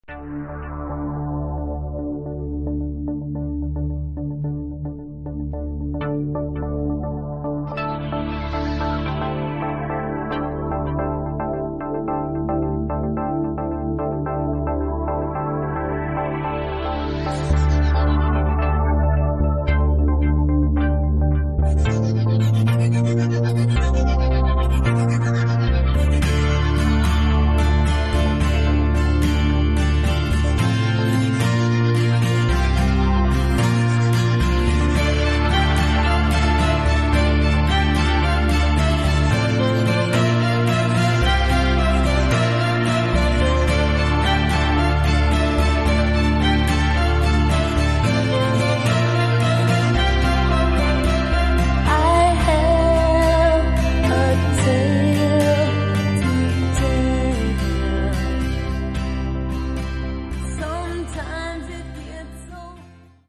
Acoustic Mix